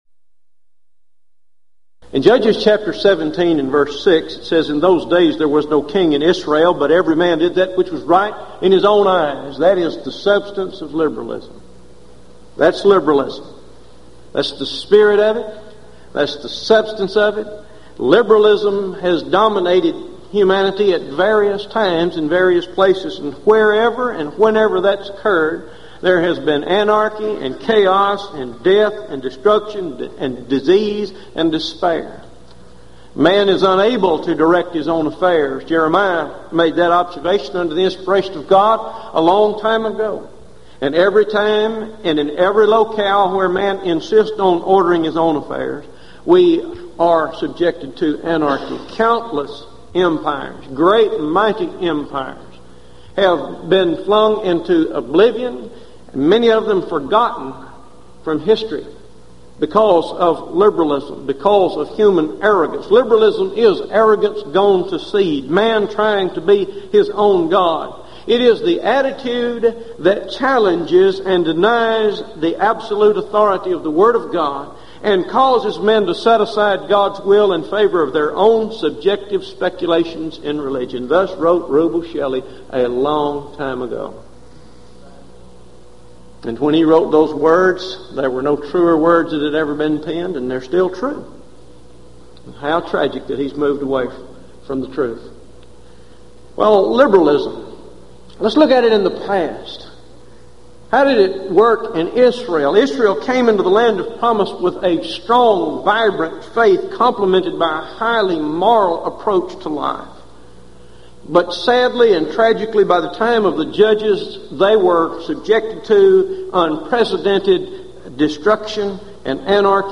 Event: 1996 Gulf Coast Lectures
lecture